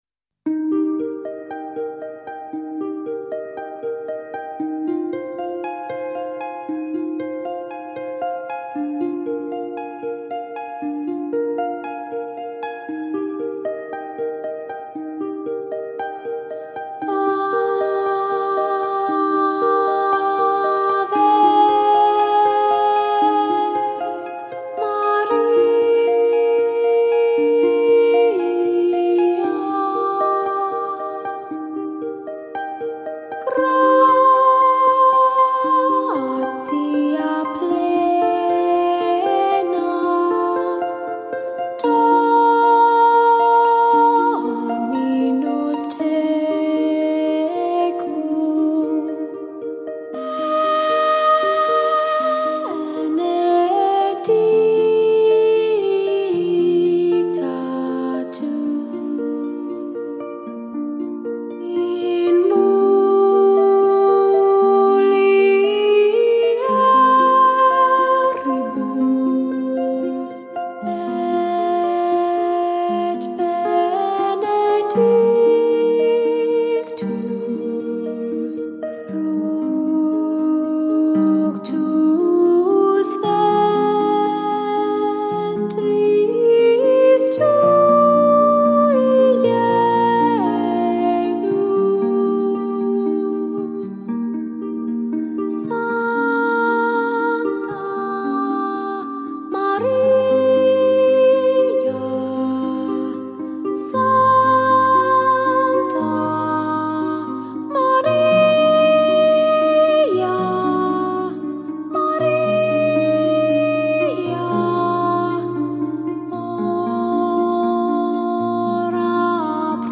听她唱歌，如沐春风：柔美、恬静、妩媚；听她唱歌，如品夏茗：清爽、舒适、怡人；
听她唱歌，如驾秋云：飘渺、浪漫、如水般流淌；听她唱歌，如围炉倾听雪花快乐的飞舞，温暖、生动、美妙……